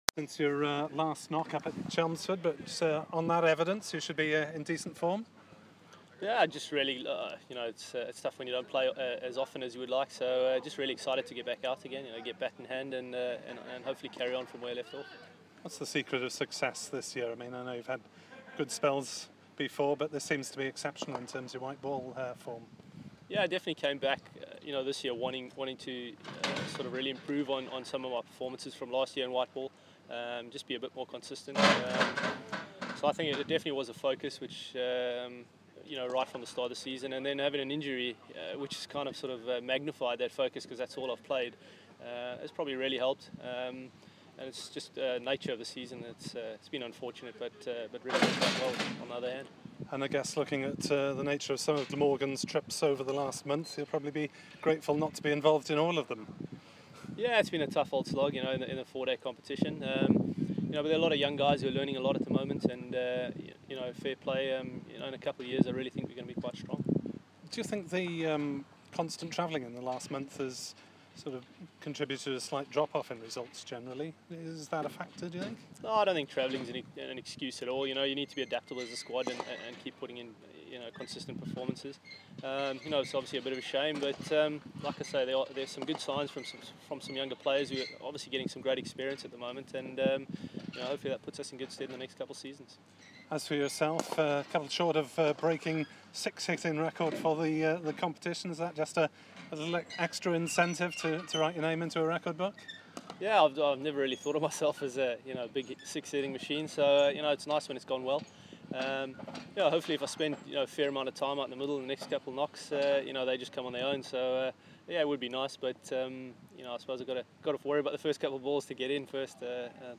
Colin Ingram chats to the media ahead of quarter-final